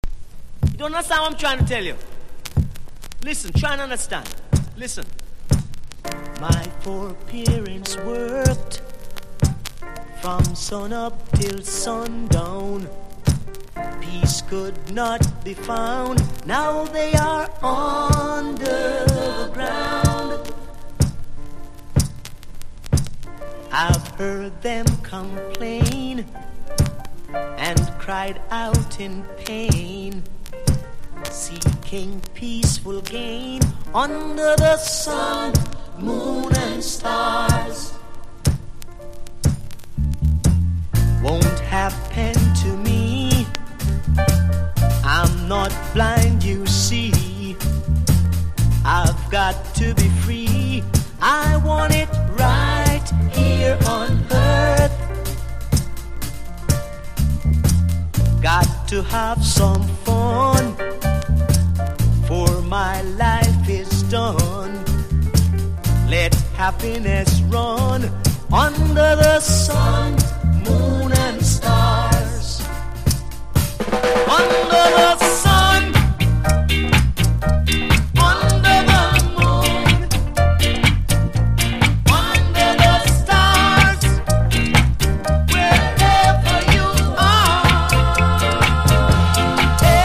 • REGGAE-SKA
1973年Dynamicスタジオで録音されたファンキーレゲエの傑作。